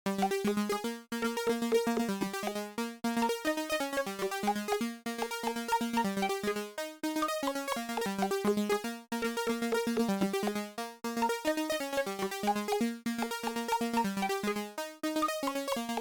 See, I recorded in the left channel the vst arp, in the right moog - MH - ARP
The first track with play with the arp is active, the second - without it